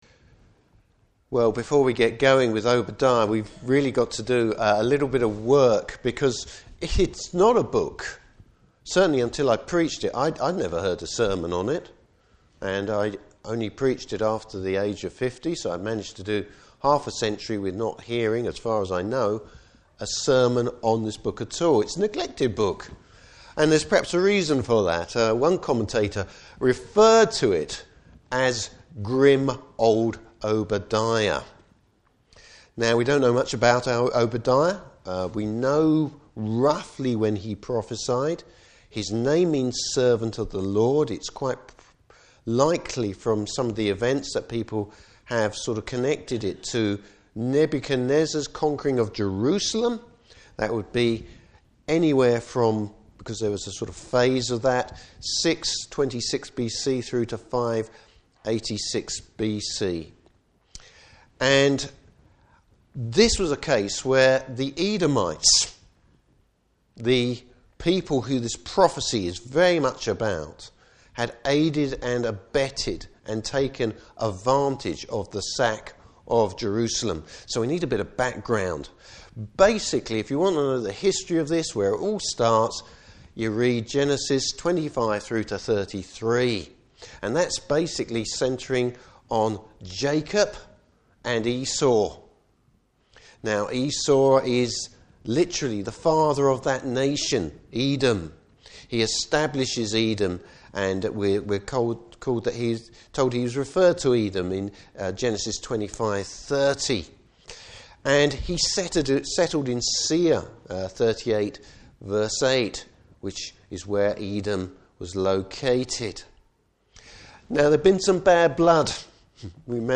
Service Type: Morning Service Bible Text: Obadiah 1-14.